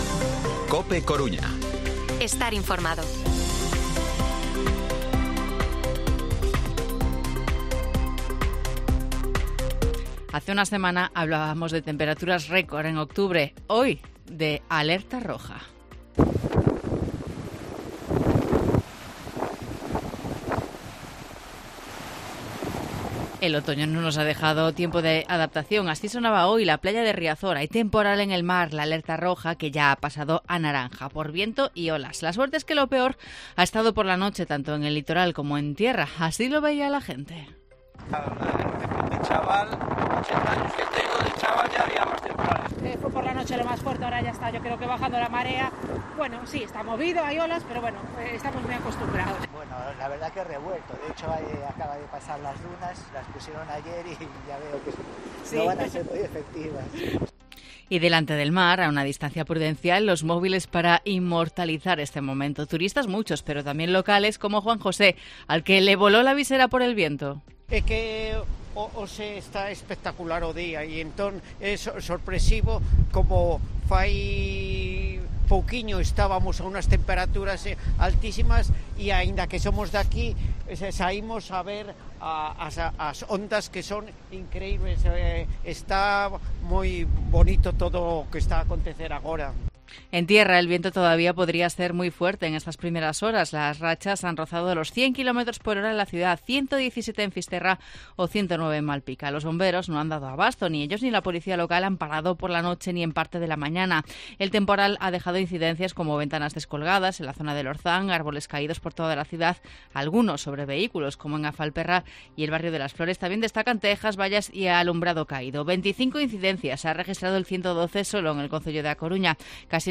Informativo Mediodía COPE Coruña viernes, 20 de octubre de 2023 14:20-14:30